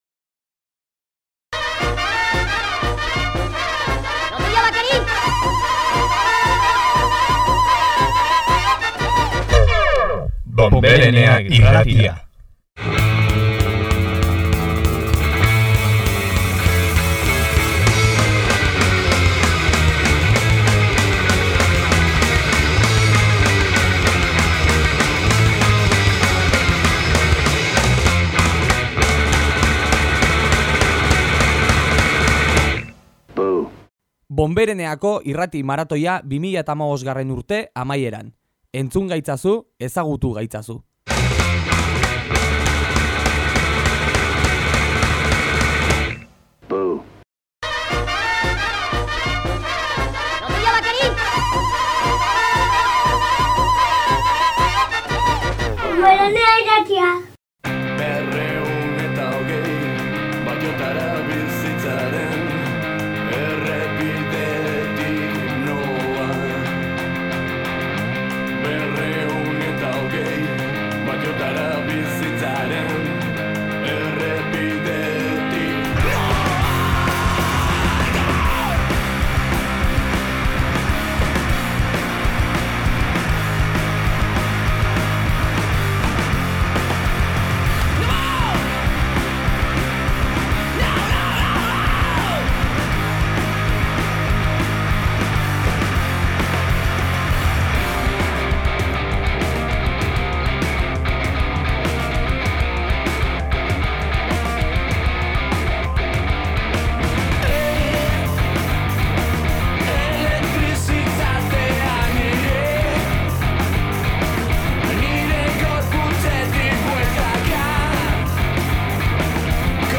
Hemen uzten dizuegu belaunaldi berrien inguruan egindako irrati saioa, Online irratiaren aurkezpenerako egin genuen irrati maratoiaren barnean.